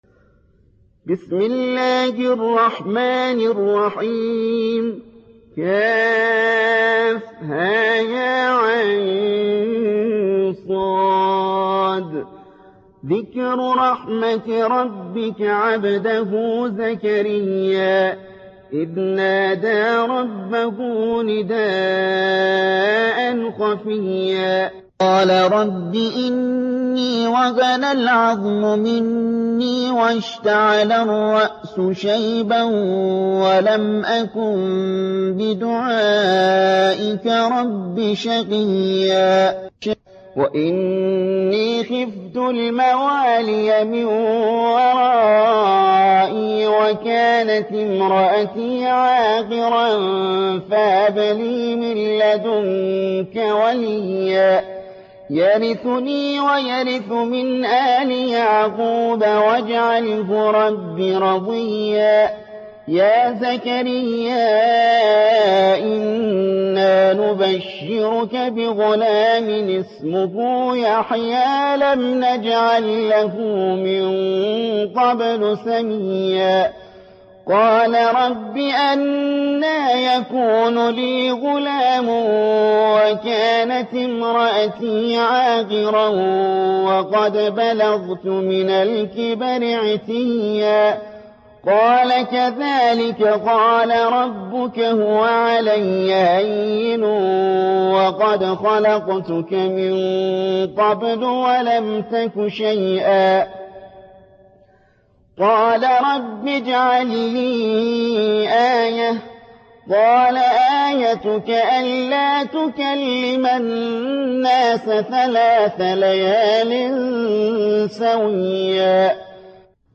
19. سورة مريم / القارئ